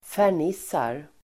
Uttal: [fär_n'is:ar]